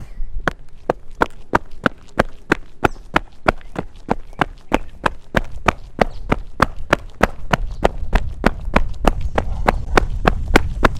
马路繁华
描述：马路喧嚣声
标签： 音效 马路 喧嚣
声道立体声